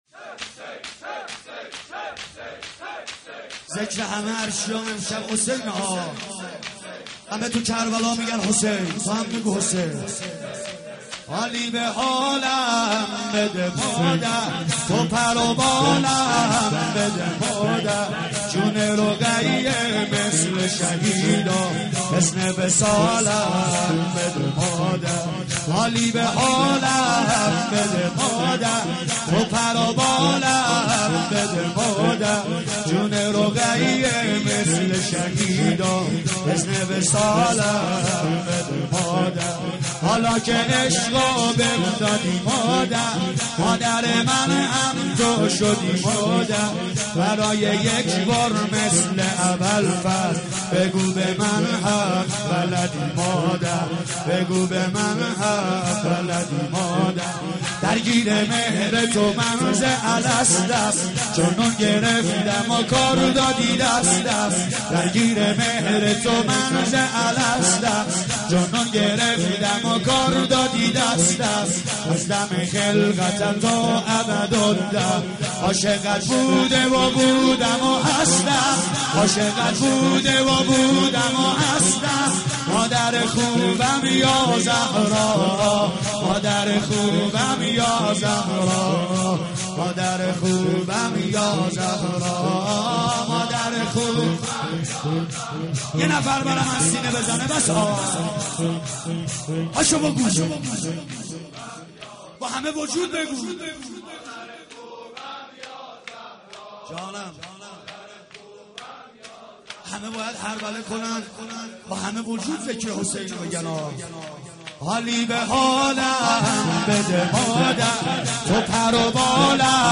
مداحی 3
شور